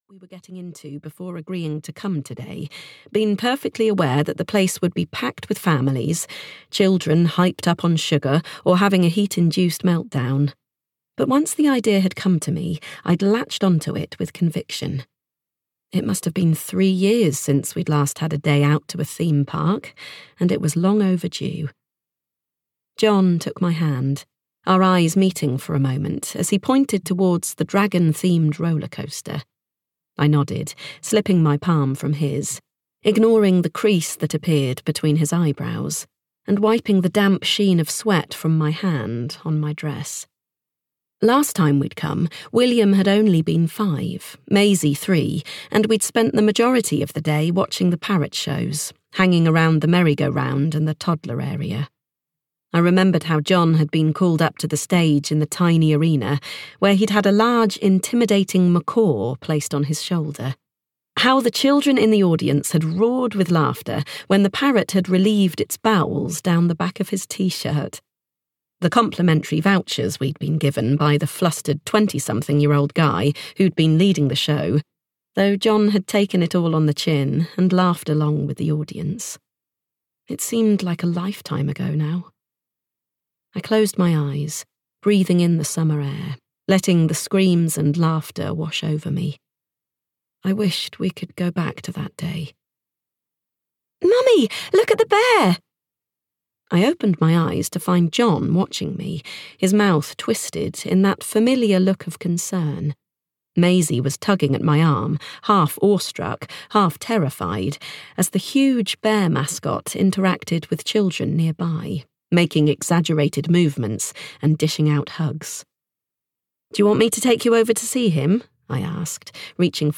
Save My Daughter (EN) audiokniha
Ukázka z knihy